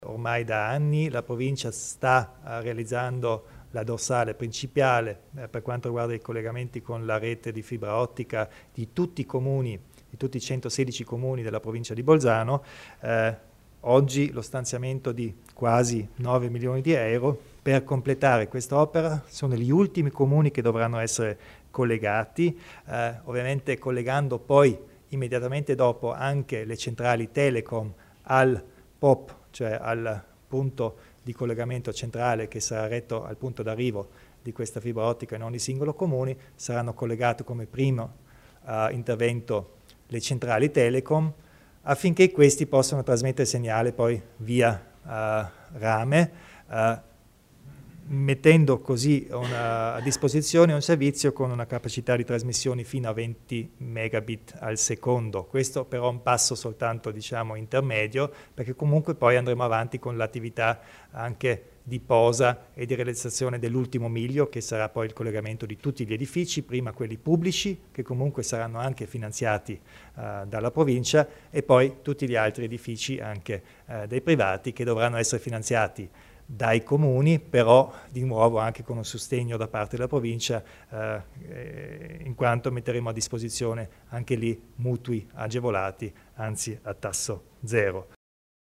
Il Presidente Kompatscher illustra i nuovi investimenti per la banda larga